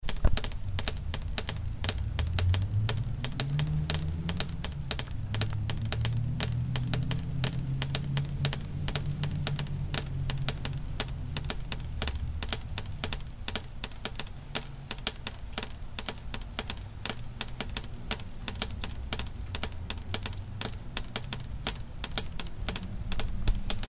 Here is an example of nature creating a groove. These are two different raindrop streams falling from a gutter, one playing three beats against the other’s four. They are slightly different tempos so they go in and out of phase with other, similar to Steve Reich’s Phasing Music. They cycle back and forth between a strong three against four – which is often found in West African rhythms – to where they are almost, but not completely in lockstep. This creates an effect that drummers call flamming.
raindrops.mp3